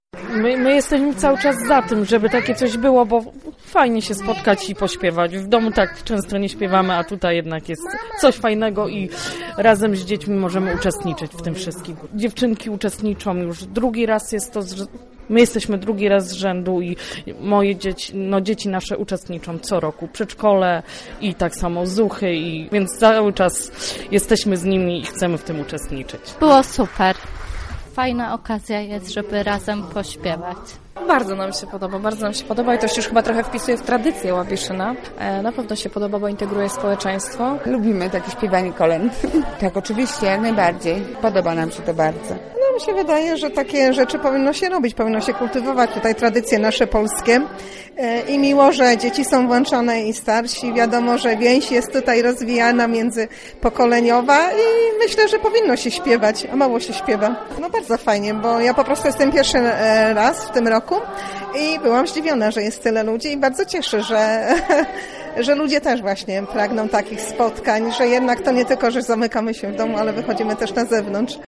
1_mieszkancy_koledowanie.mp3